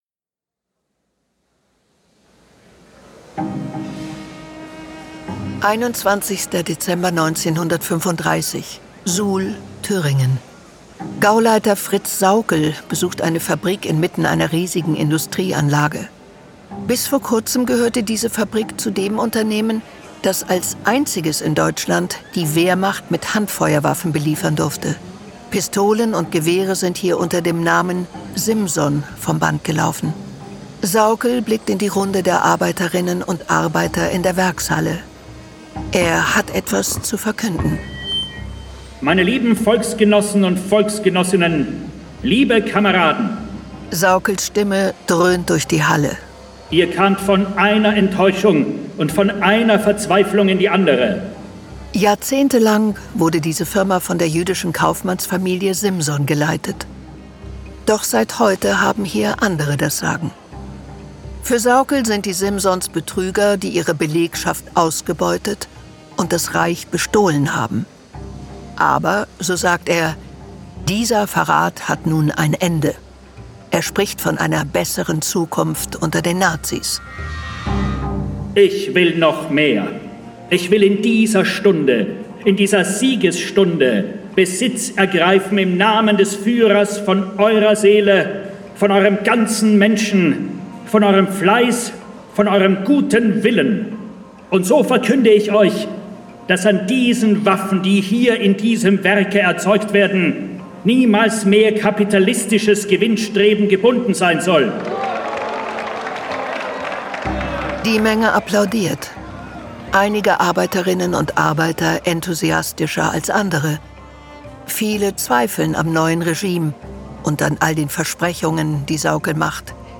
Sprecherin: Iris Berben